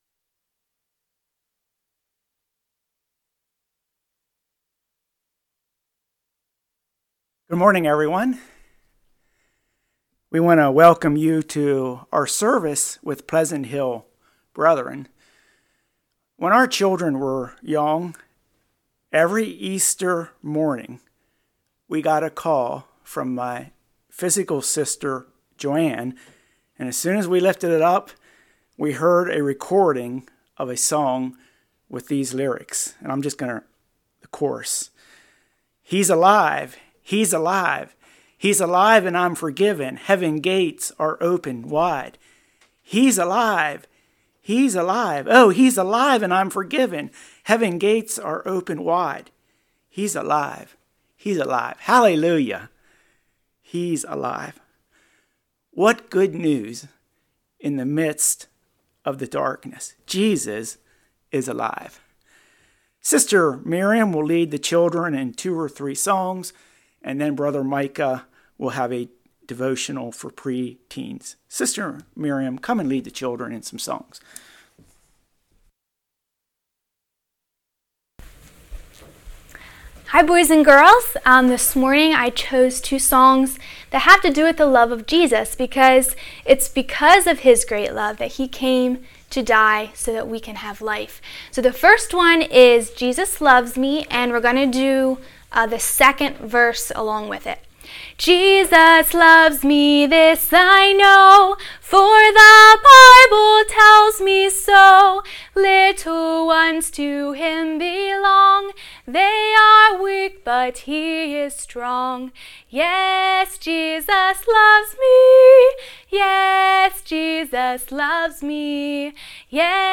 John 5:17-29 Service Type: Morning Who is Jesus?